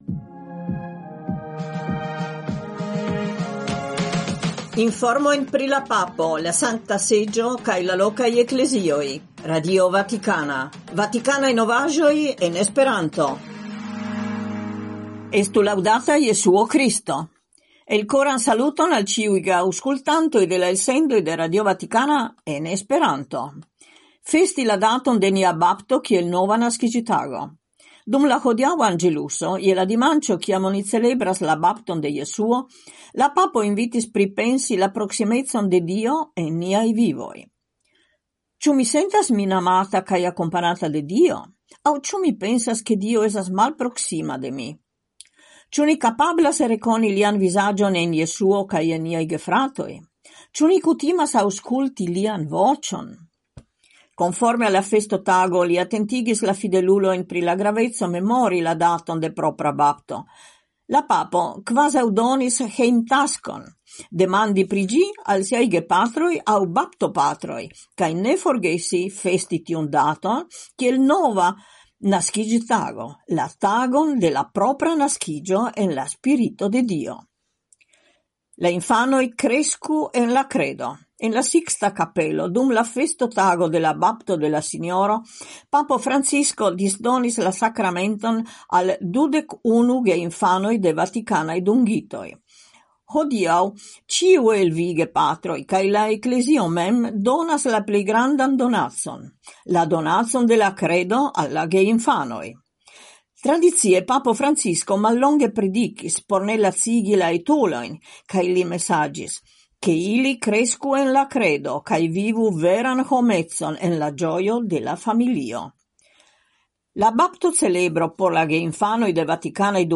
Elsendoj kun informoj el Vatikano en esperanto (trifoje semajne, merkrede, ĵaŭde kaj dimanĉe, horo 20.20 UTC). Ekde 1977 RV gastigas elsendojn en esperanto, kiuj informas pri la agado de la Papo, de la Sankta Seĝo, de la lokaj Eklezioj, donante spacon ankaŭ al internaciaj informoj, por alporti la esperon de la kredo kaj proponi interpreton de la faktoj sub la lumo de la Evangelio.